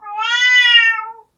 猫（ねえ〜）３c